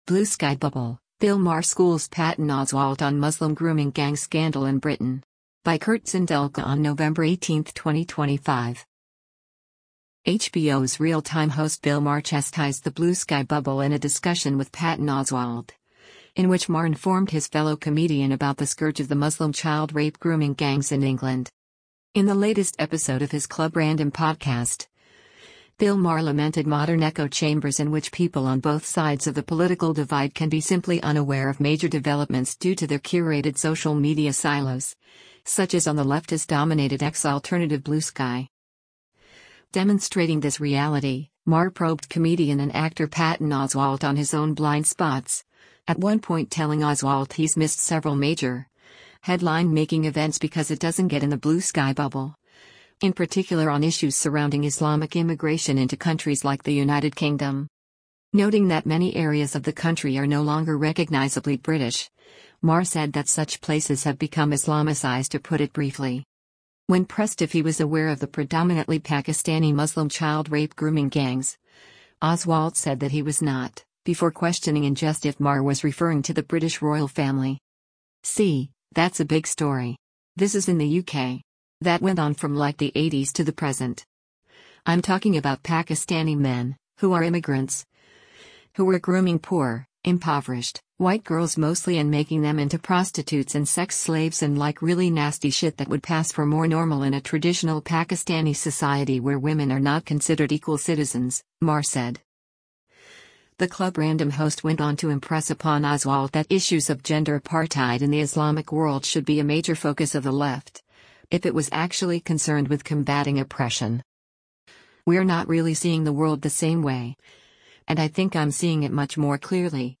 HBO’s “Real Time” host Bill Maher chastised the “Bluesky bubble” in a discussion with Patton Oswalt, in which Maher informed his fellow comedian about the scourge of the Muslim child rape grooming gangs in England.